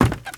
High Quality Footsteps
Wood, Creaky
STEPS Wood, Creaky, Run 24.wav